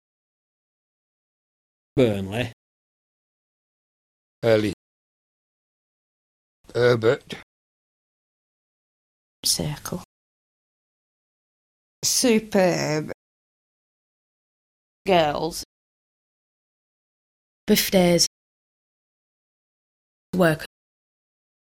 Listen to nonrhotic examples from the Burnley-Colne area:
Example 3: nonrhotic NURSE vowels
burnleynurse.mp3